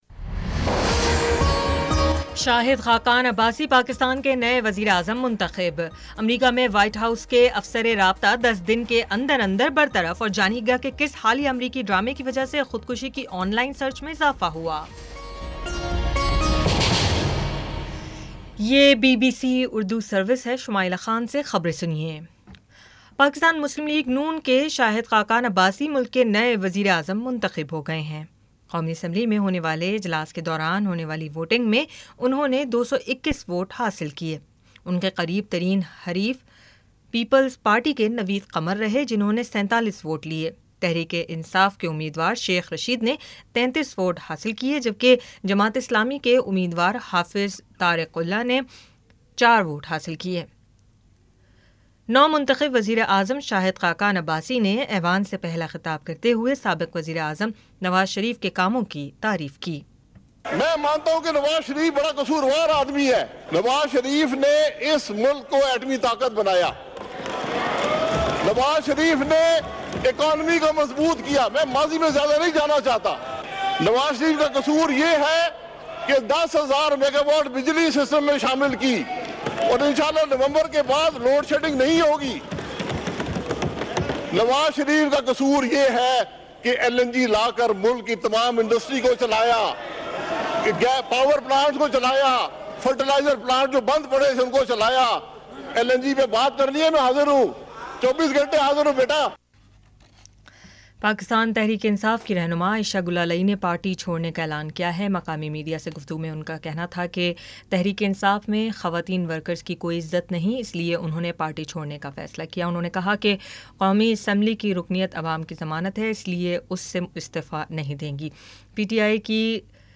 اگست 01 : شام سات بجے کا نیوز بُلیٹن